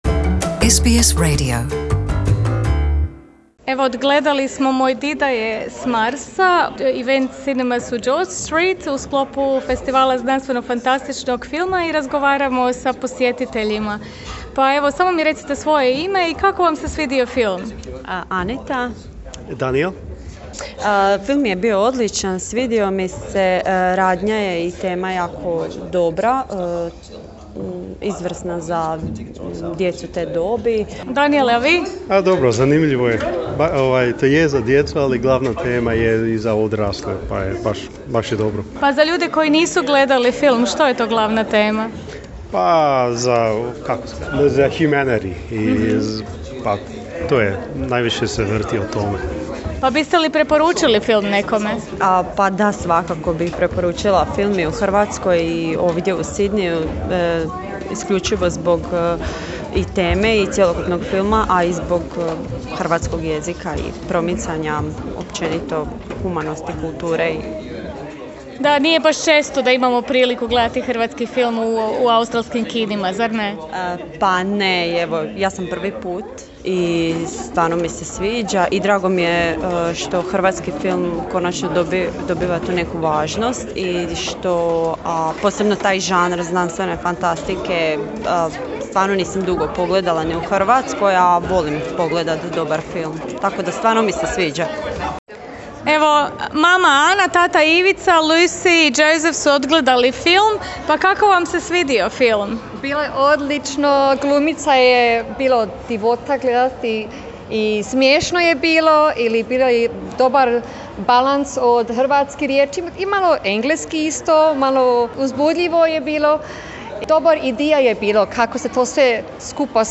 SBS Croatian bio je na projekciji obiteljskoga filma i zabilježio reakcije gledatelja